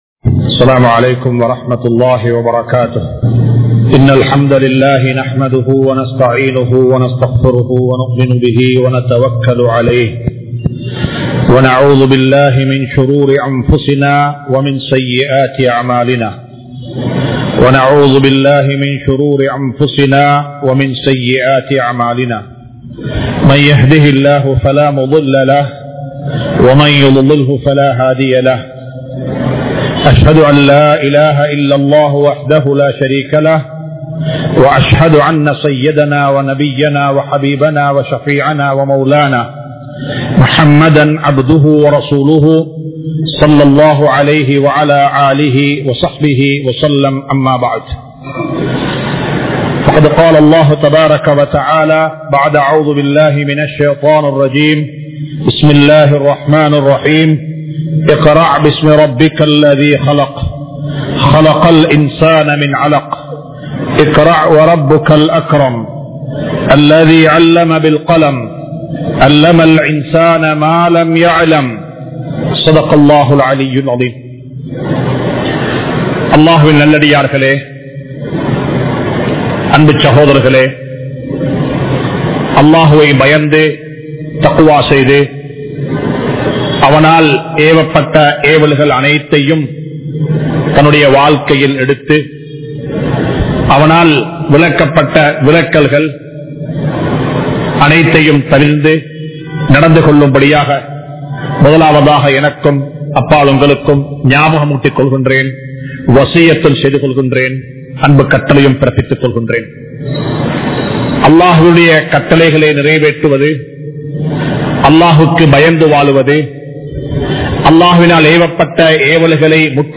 Muthalil Islaathai Padiungal (முதலில் இஸ்லாத்தை படியுங்கள்) | Audio Bayans | All Ceylon Muslim Youth Community | Addalaichenai
Majma Ul Khairah Jumua Masjith (Nimal Road)